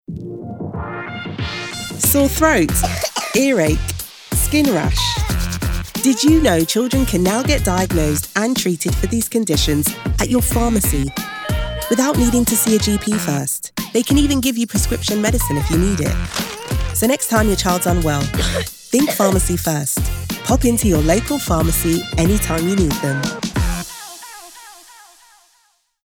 NHS Pharmacy First Spotify Radio Ad